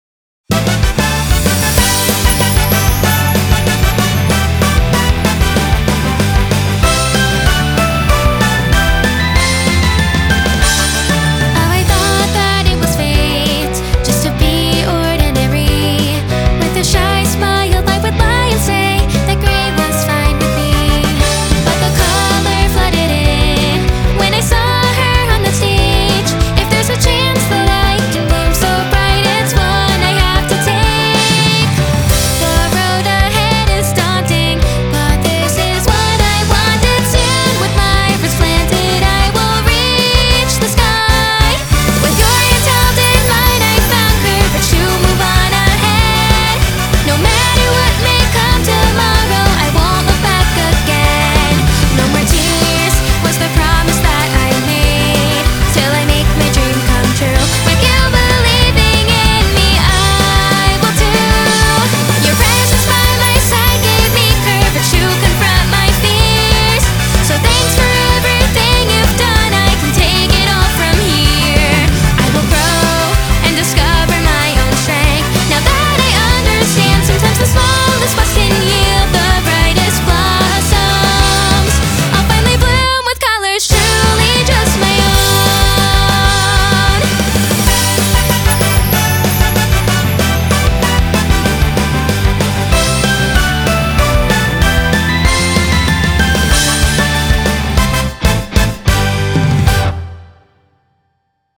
BPM190
Audio QualityPerfect (High Quality)
Song type: Web original